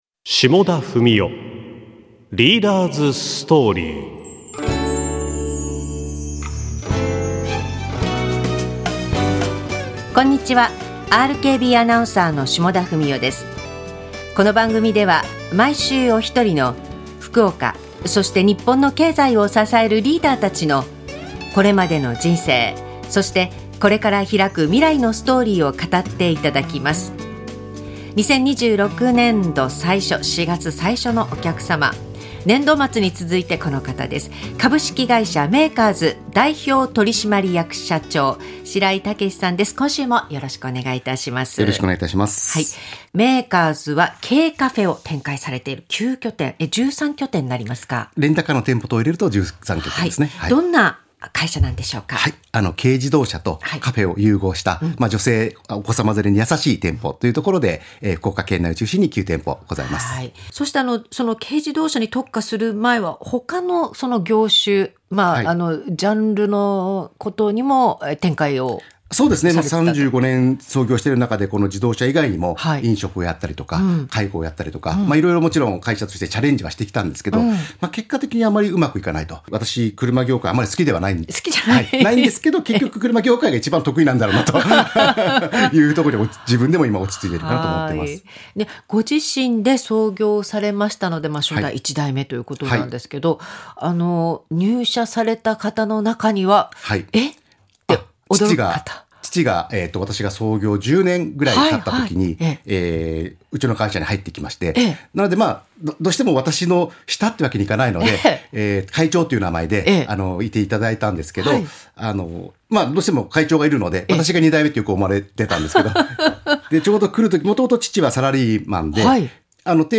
ラジオ